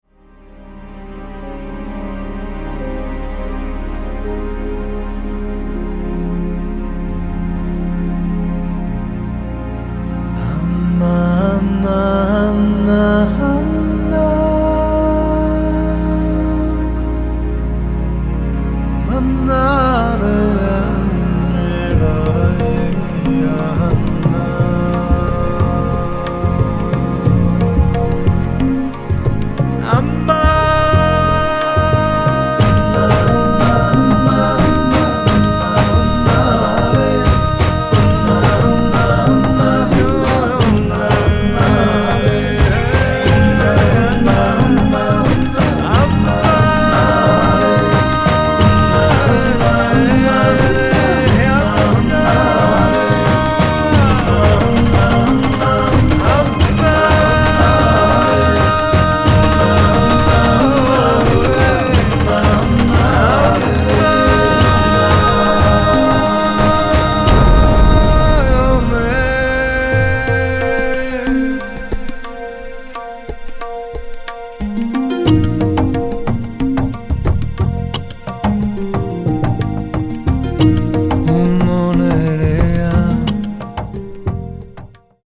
world chant and groove music